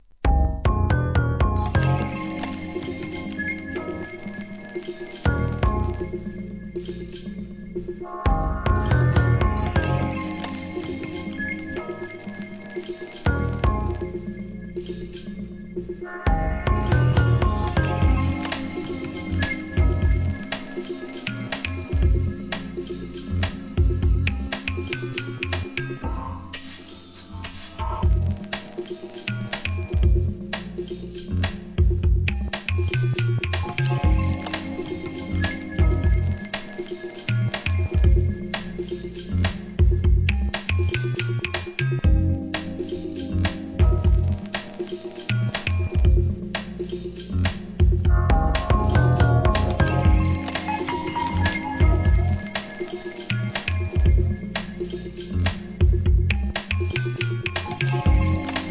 洒落乙フレンチ・テイストなダウンビート、ドープ・ブレイクビーツ、エレクトロ等で構成された2000年作品!!